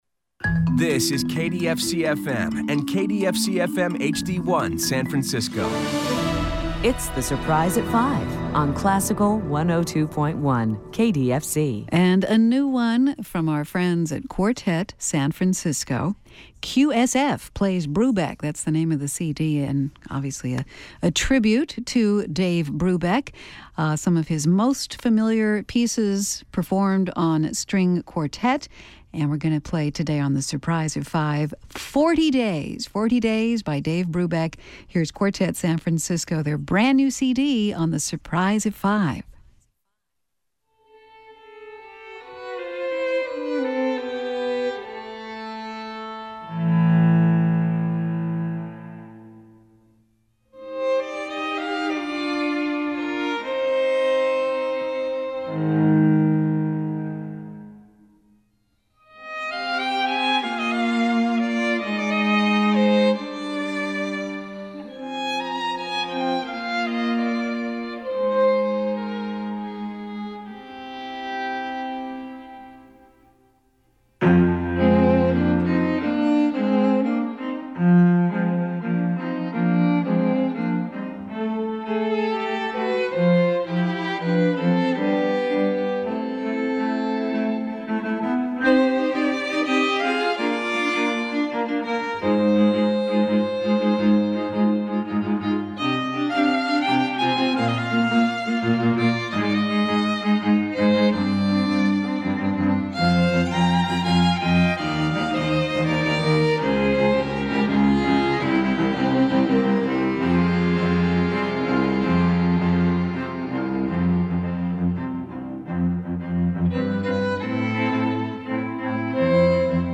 String quartet arrangements